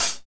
Index of /90_sSampleCDs/Northstar - Drumscapes Roland/DRM_Medium Rock/HAT_M_R Hats x